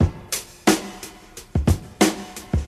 119 Bpm Drum Groove F# Key.wav
Free drum loop sample - kick tuned to the F# note.
119-bpm-drum-groove-f-sharp-key-mYr.ogg